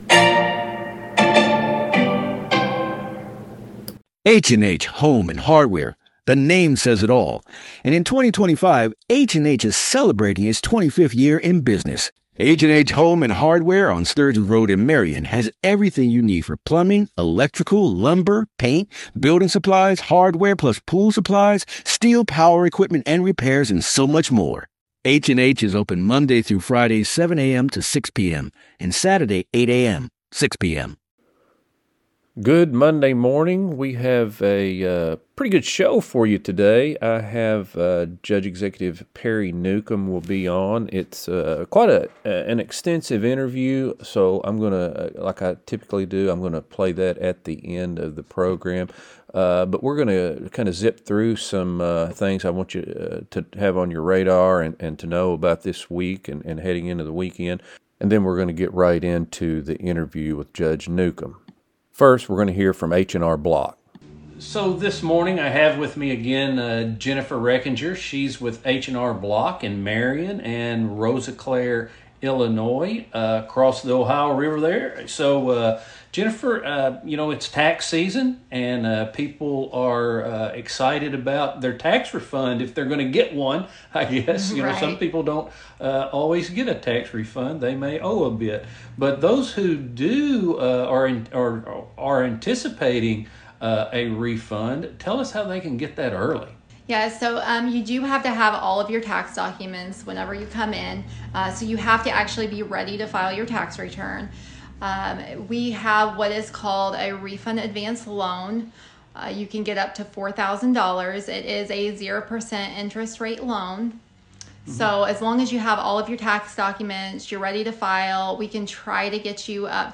HOME AND HARDWARE THURSDAY | LOCAL NEWScast LISTEN NOW News | Sports | Interviews Joined this morning by Judge-Exec Perry Newcom By Crittenden Press Online at February 19, 2026 Email This BlogThis!